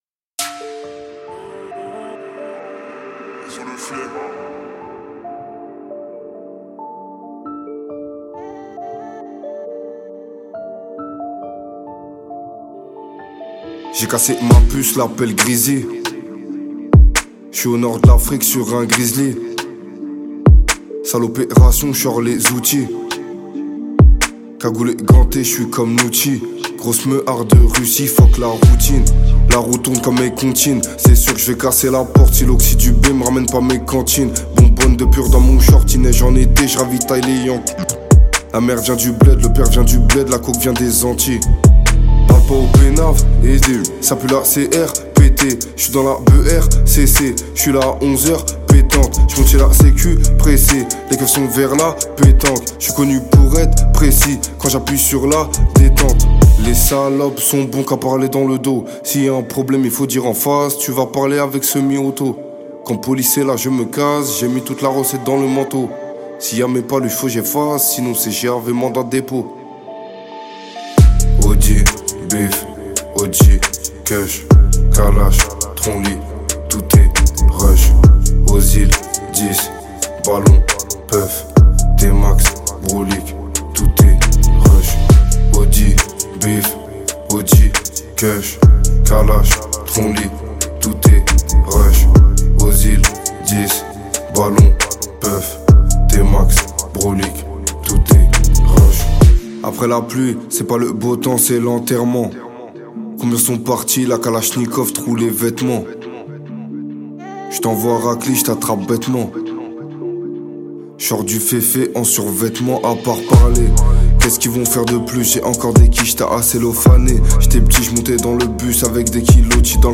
38/100 Genres : french rap, pop urbaine Télécharger